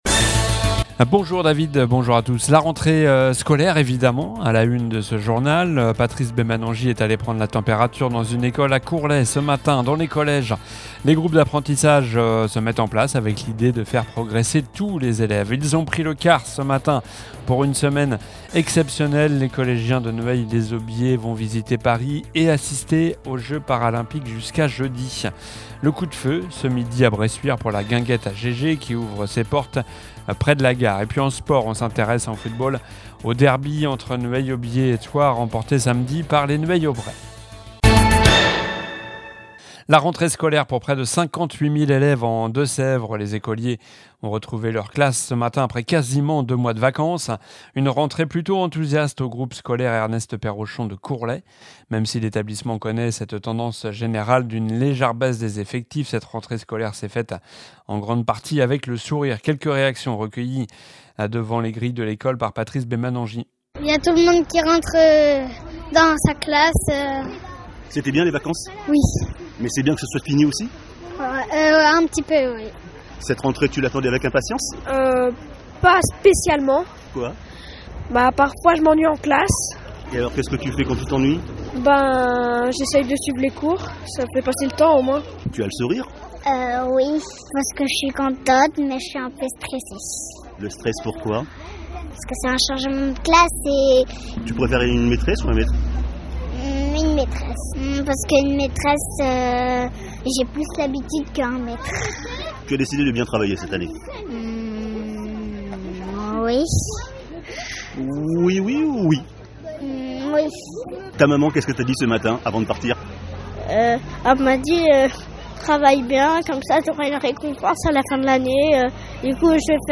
ambiance dans une école à Courlay... - Dans les collèges, les groupes d'apprentissage se mettent en place - Une rentrée exceptionnelle pour les collégiens de Nueil-les-Aubiers qui vont visiter Paris et assister aux Jeux Paralympiques.